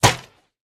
Minecraft Version Minecraft Version latest Latest Release | Latest Snapshot latest / assets / minecraft / sounds / item / crossbow / shoot2.ogg Compare With Compare With Latest Release | Latest Snapshot
shoot2.ogg